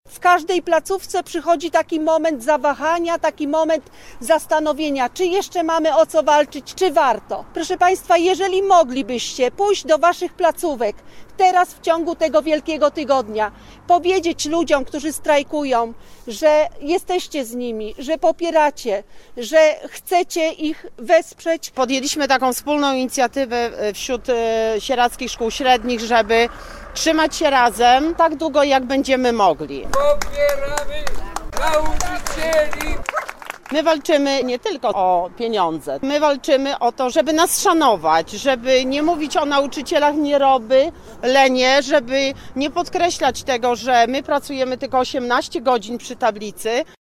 Mieszkańcy Sieradza, nauczyciele i uczniowie spotkali się (15 kwietnia) na wieczornym wiecu poparcia dla strajkujących pracowników oświaty.
Nazwa Plik Autor Wiec poparcia w Sieradzu audio (m4a) audio (oga) Warto przeczytać Pogoda na piątek.